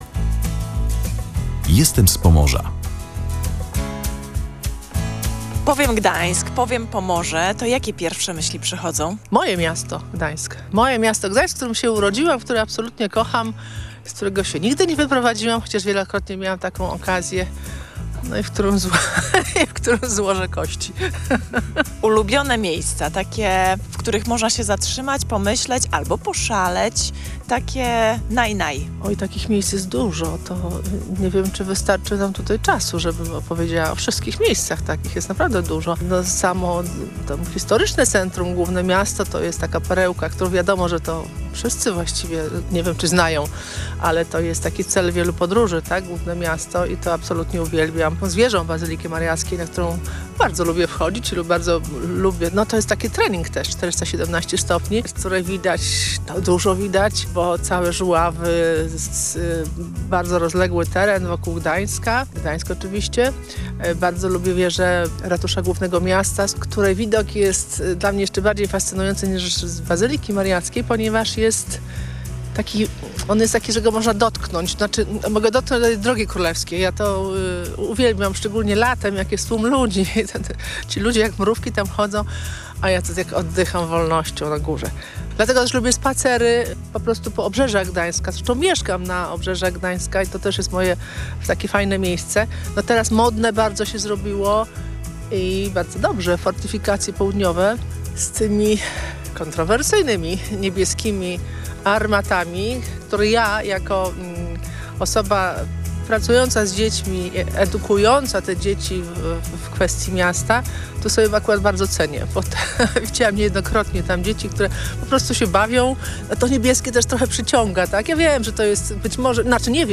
w jednym z gdańskich przedszkoli